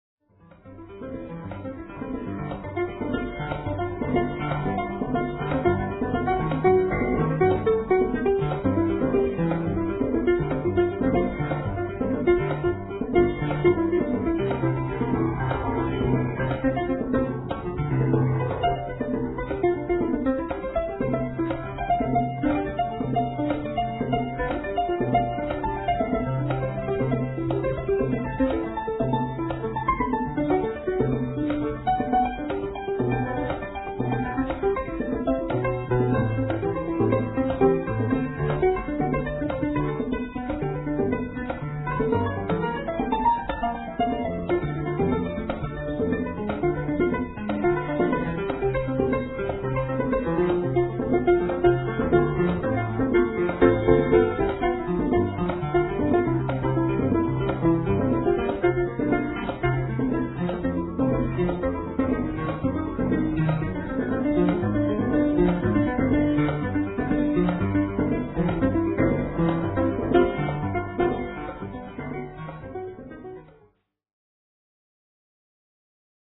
Ich habe also getan, wie mir in Post #18 "geheißen" ... das ist aber eine rein technische Umsetzung ... ohne Dynamik und so weiter. Außerdem habe ich aus Bequemlichkeit darauf verzichtet, die gesamte Begleitung im Klavier auf Bossa umzubauen, und habe die original belassen.